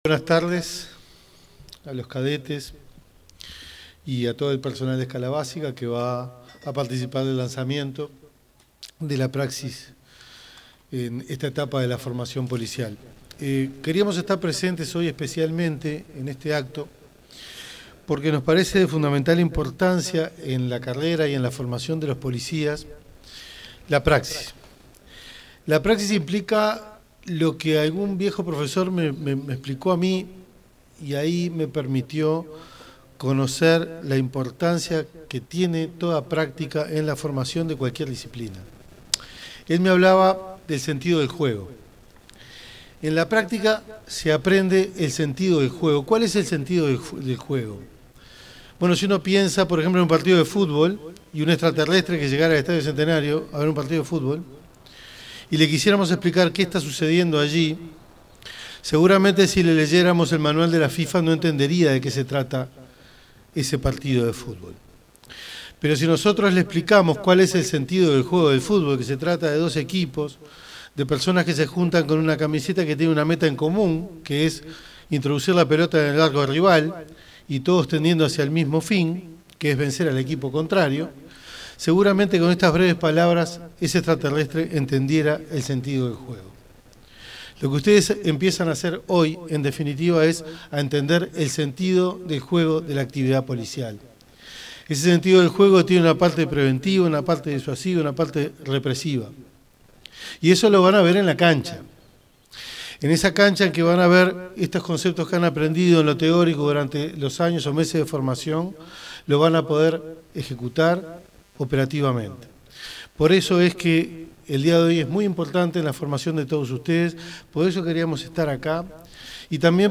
Palabras del ministro del Interior, Carlos Negro
El ministro del Interior, Carlos Negro, se expresó durante la ceremonia de inicio de prácticas de alumnos y cadetes de la Escuela Nacional de Policía.